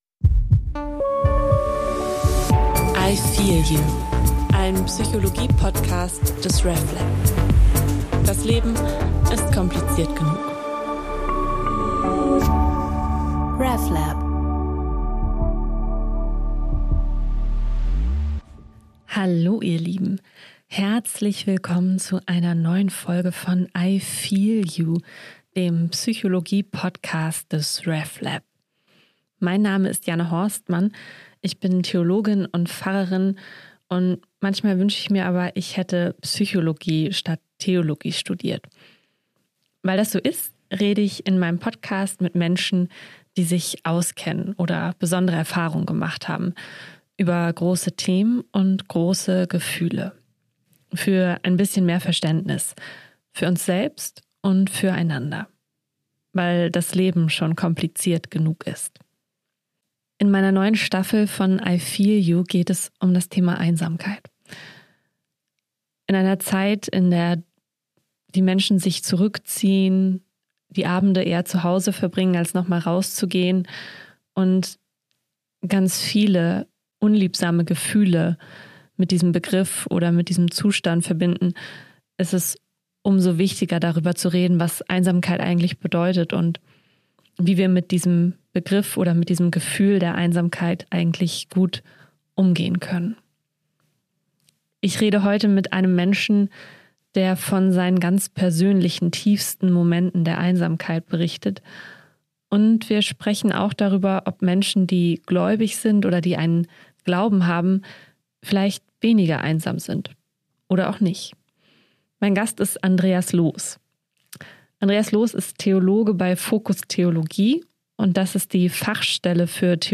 Es ist ein sehr intimes Gespräch geworden